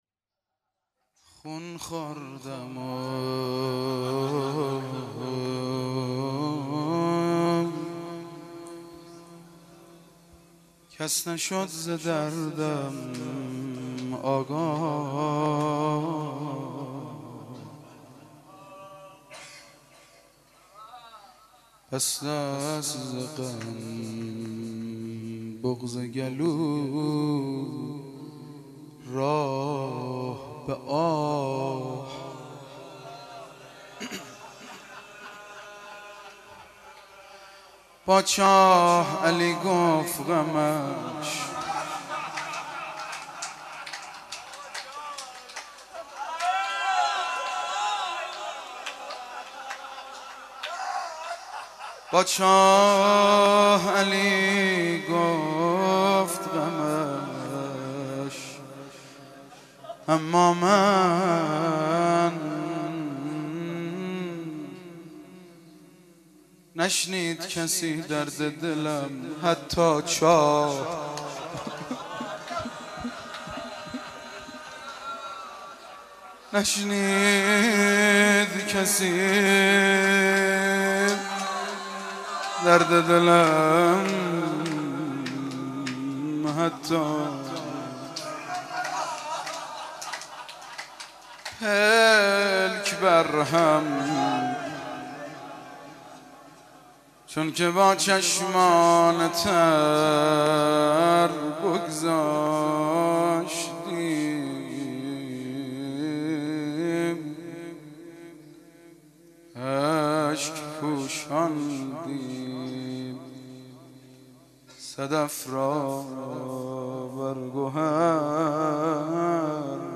11-Rozeh.mp3